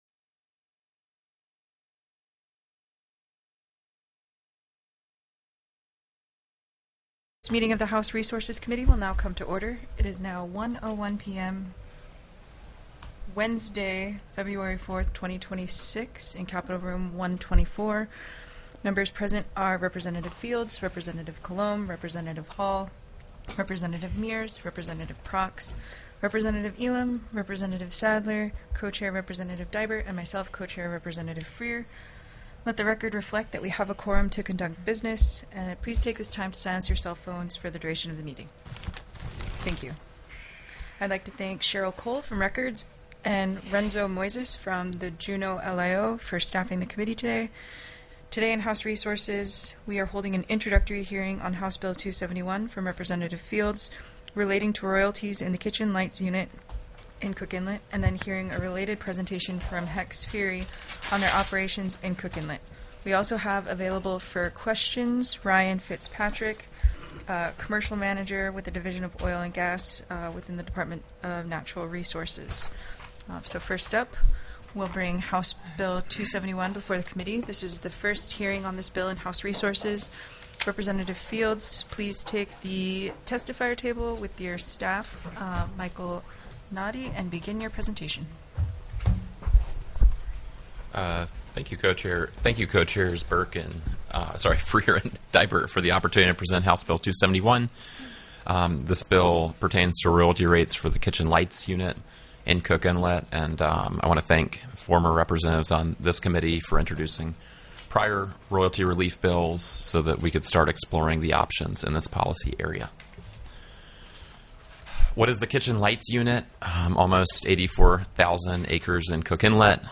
The audio recordings are captured by our records offices as the official record of the meeting and will have more accurate timestamps.
HB 271 KITCHEN LIGHTS UNIT ROYALTY MODIFICATION TELECONFERENCED